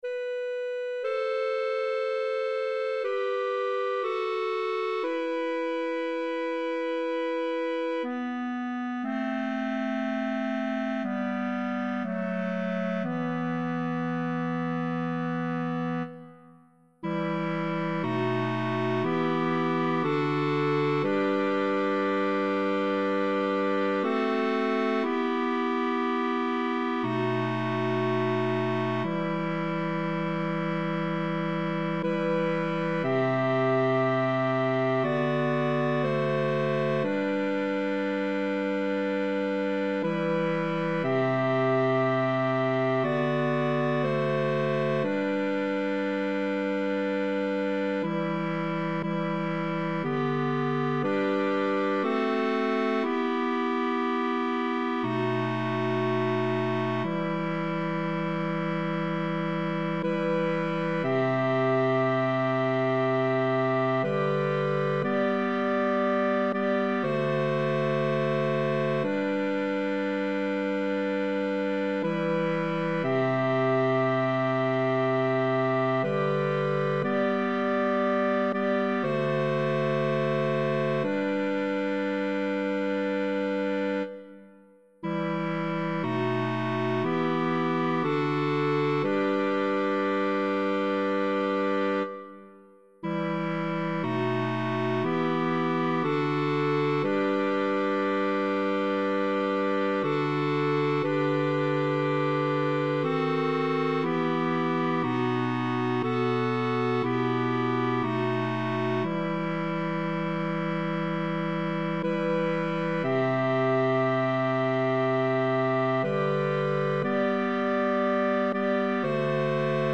Voci (mp3): sopran, alto, tenor, bas, cor mixt